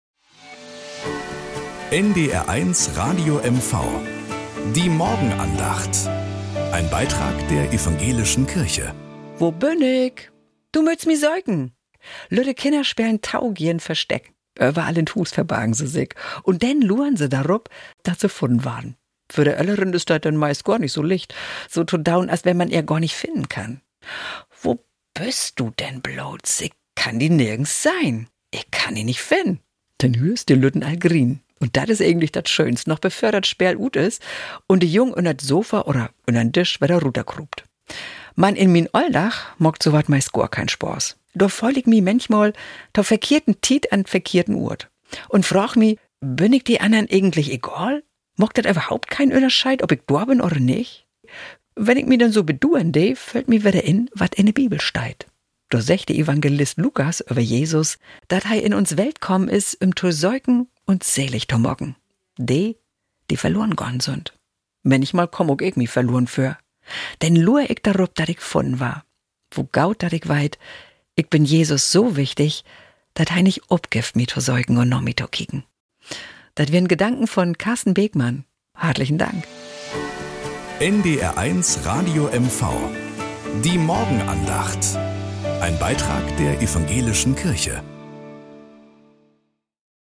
Morgenandacht auf NDR 1 Radio MV